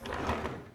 household
Drawer Runner Slide Out 3